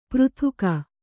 ♪ přrhuka